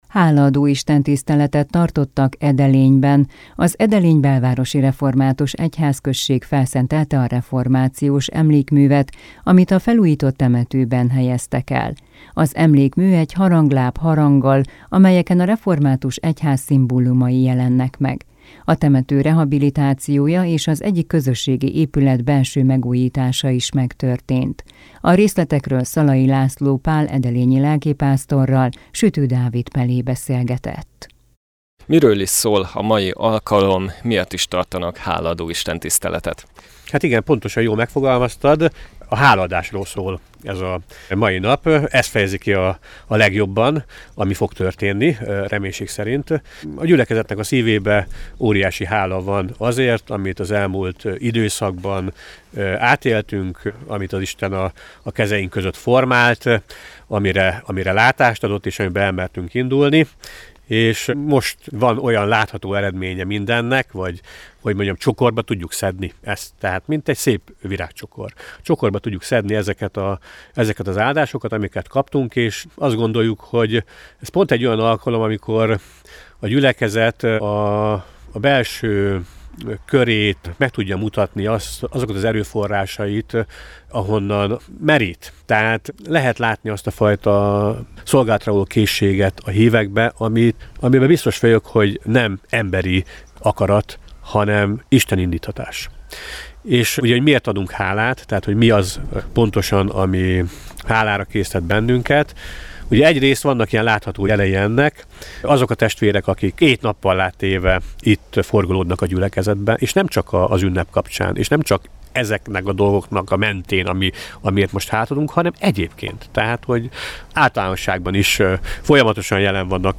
Hálaadó istentiszteletet tartottak Edelényben